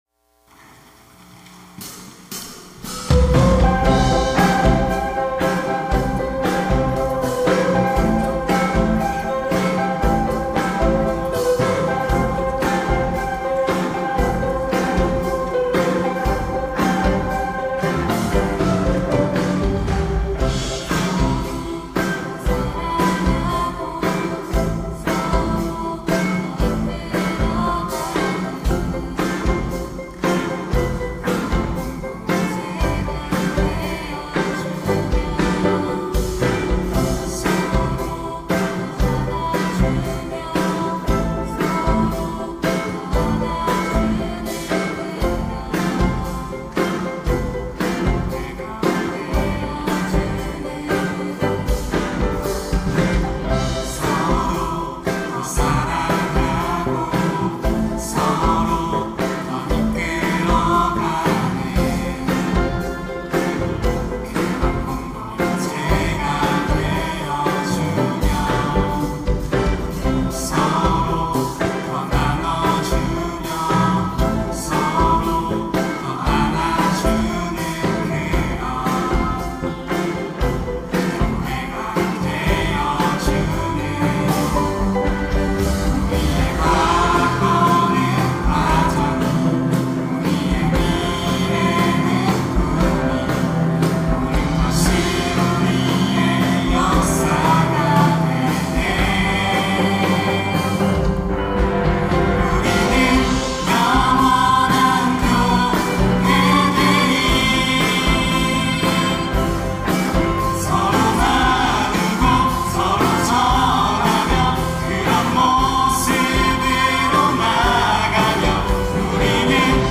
특송과 특주 - 서로
천안중앙교회
이름 청년부 2025년 4팀 3셀&6셀